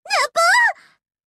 nepu-dialogue_1.mp3